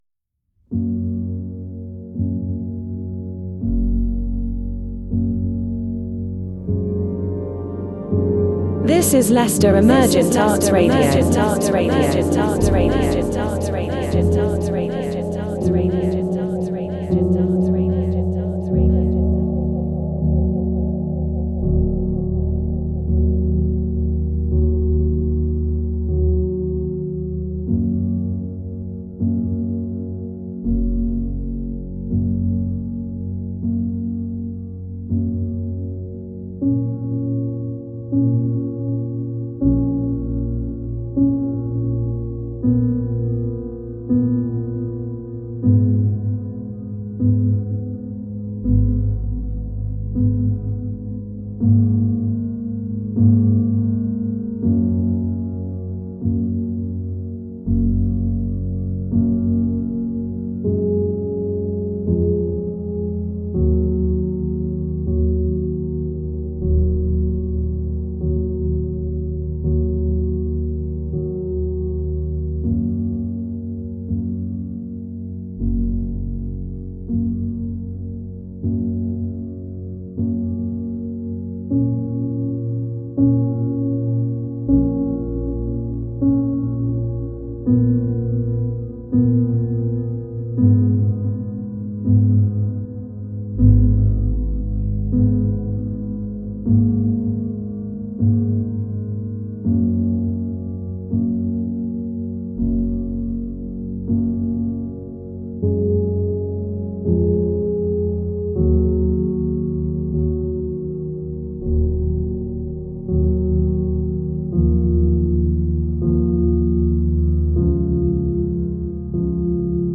evoking the serene yet fleeting beauty of twilight.